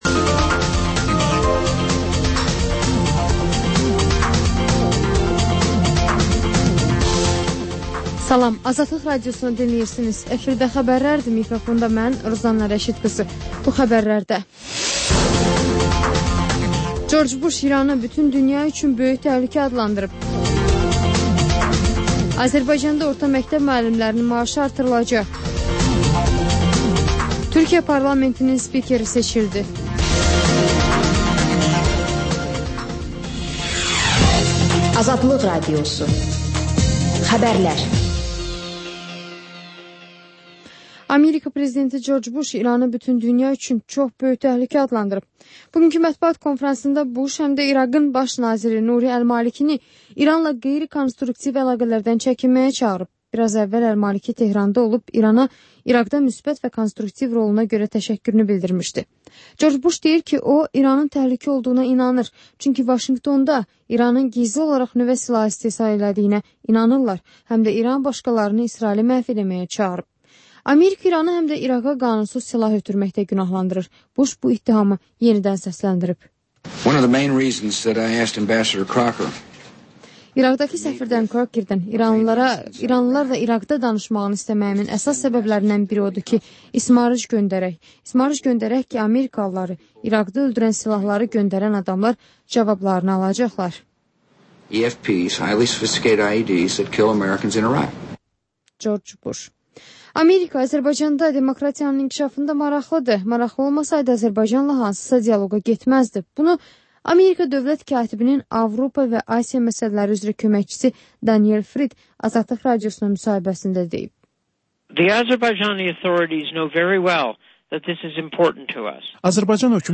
Xəbərlər, müsahibələr, hadisələrin müzakirəsi, təhlillər, sonda QAFQAZ QOVŞAĞI rubrikası: «Azadlıq» Radiosunun Azərbaycan, Ermənistan və Gürcüstan redaksiyalarının müştərək layihəsi